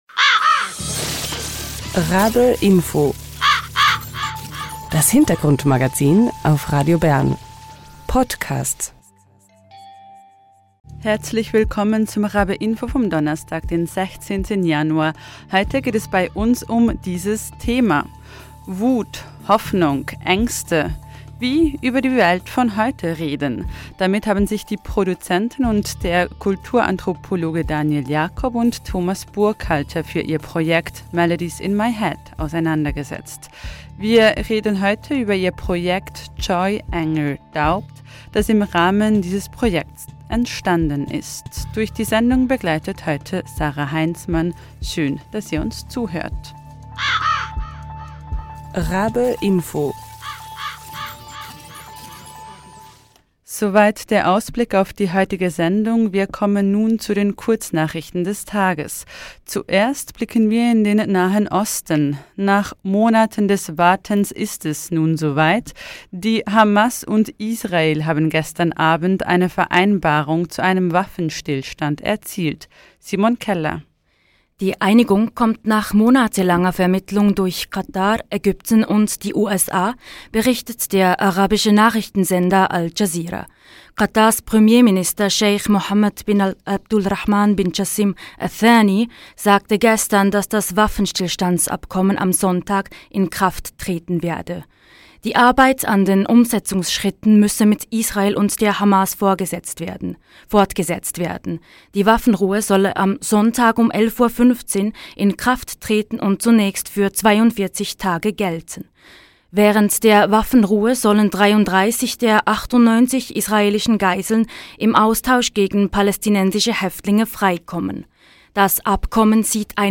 Wir sprechen mit den beiden Kunstschaffenden im Live-Talk.